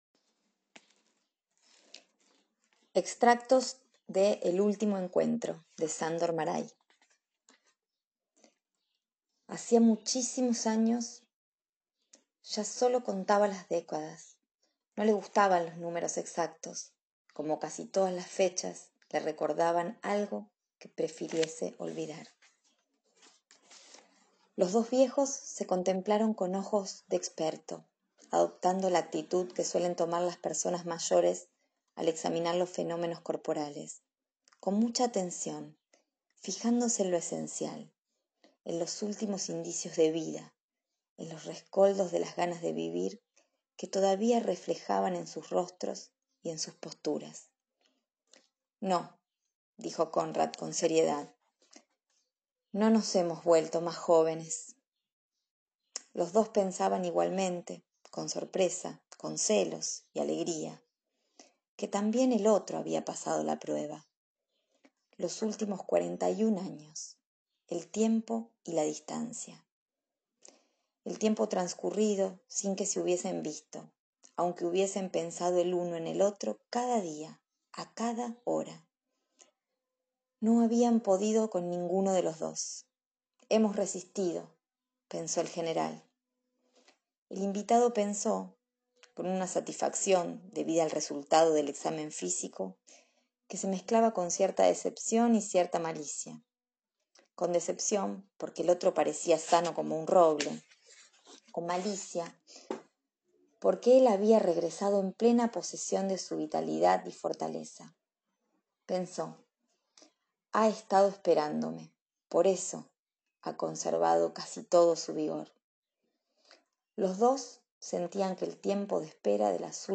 "El último encuentro" novela de Sandor Marai; extractos leídos